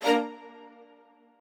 strings5_27.ogg